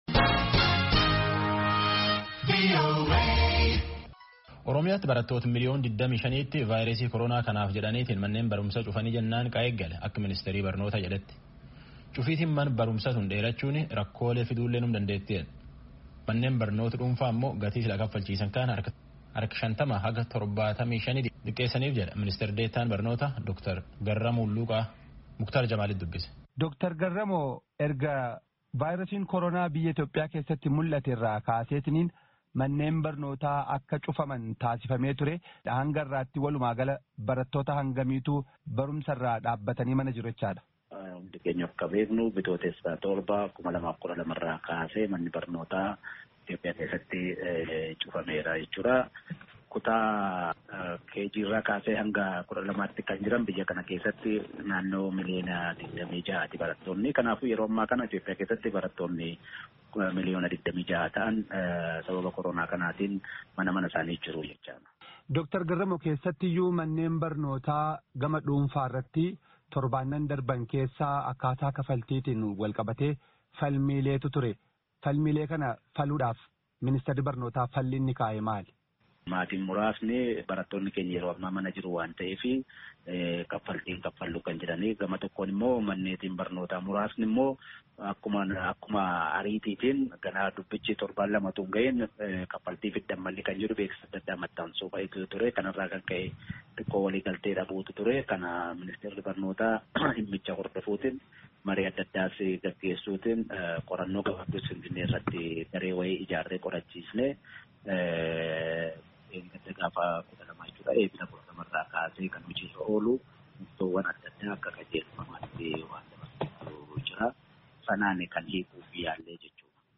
Gaaffii fi deebii Dr. Garamoo waliin geggeessame Kutaa 1ffaa